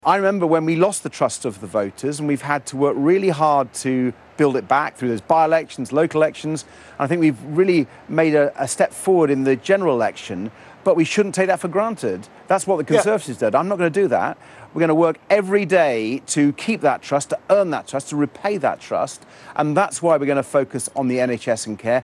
Sir Ed Davey Speaking to Sky News